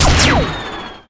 pshoot1.ogg